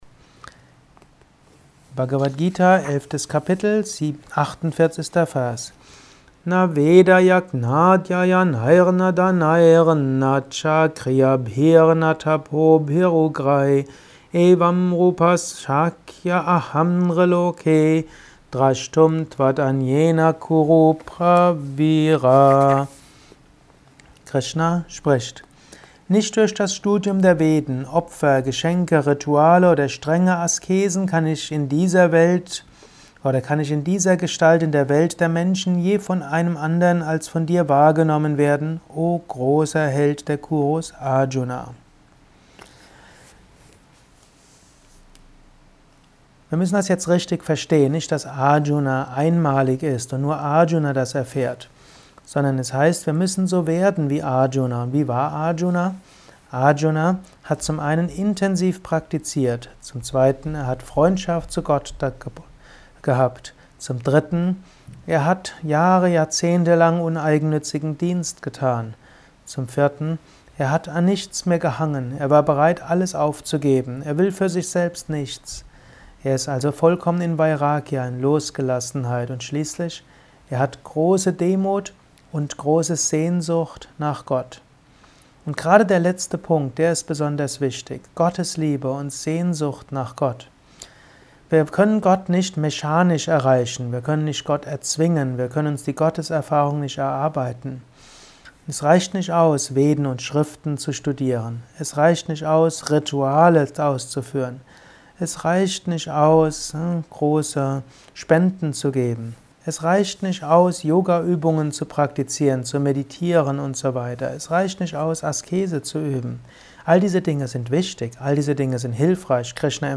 Dies ist ein kurzer Kommentar als